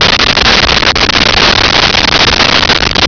Sfx Amb Stationhall Loop
sfx_amb_stationhall_loop.wav